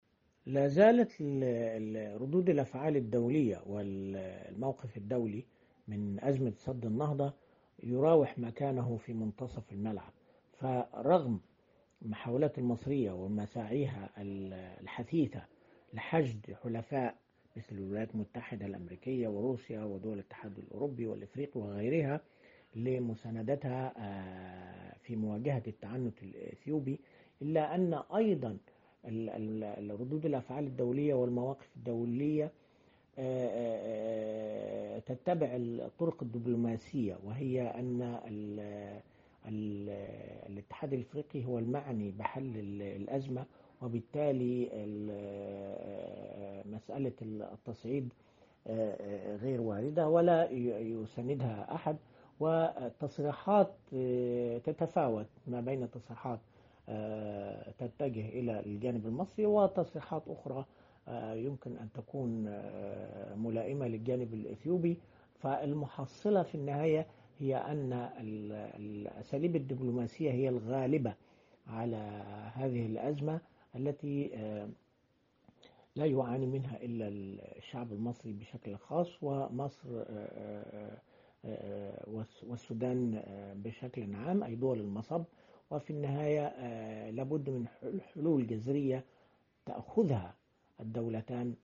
كاتب صحفي ومحلل سياسي